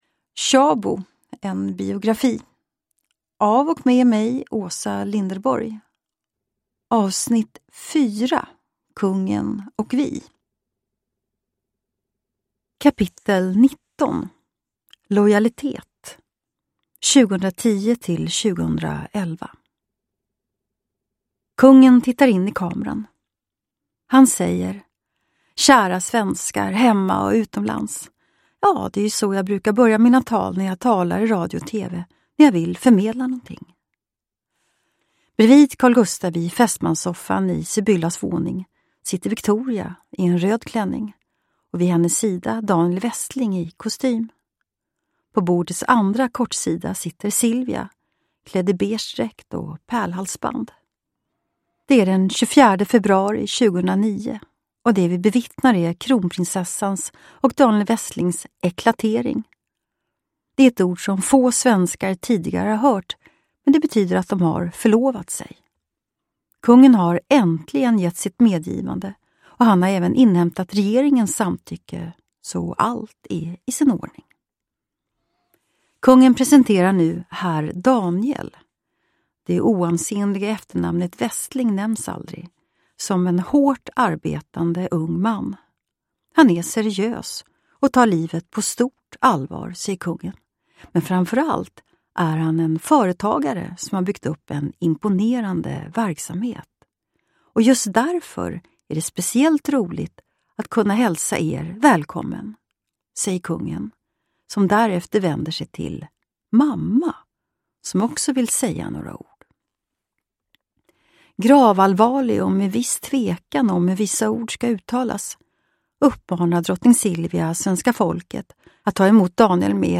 Uppläsare: Åsa Linderborg
Ljudbok